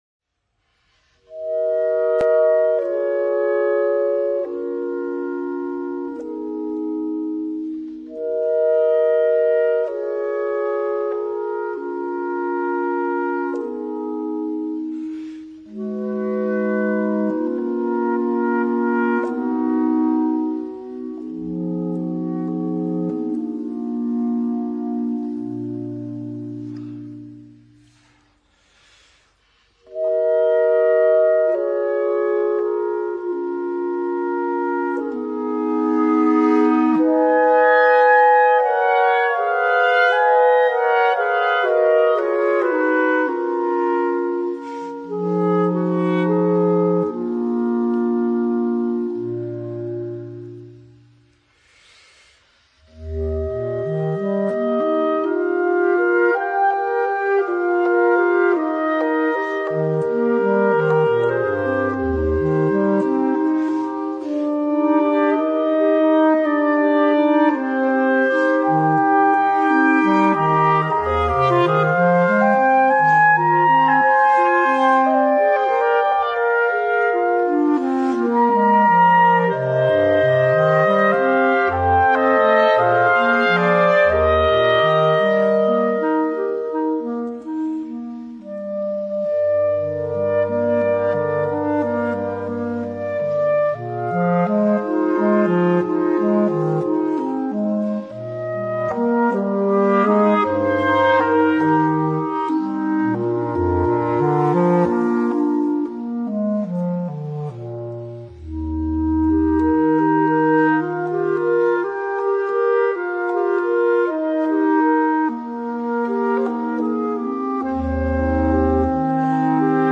per quartetto di clarinetti
suggerisce un’atmosfera magica e ispirata